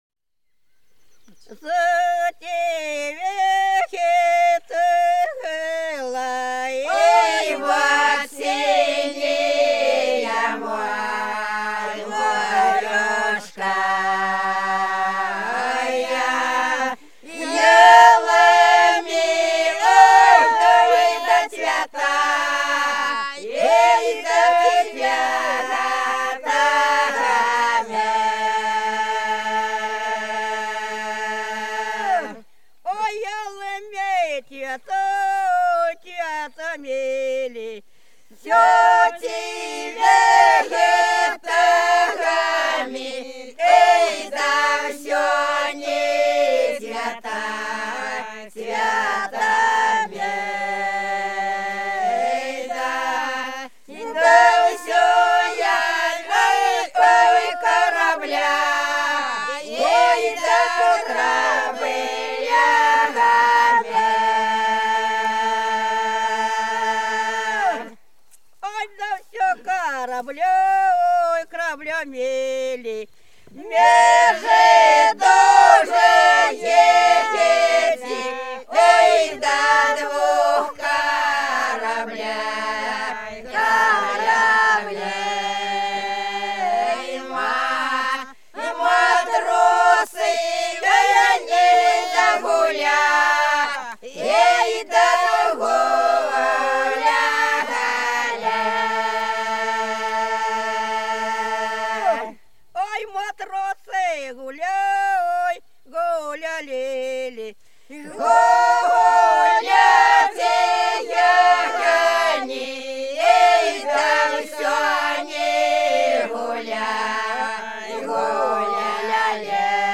За речкою диво Зацветало сине море - протяжная (с. Пузево)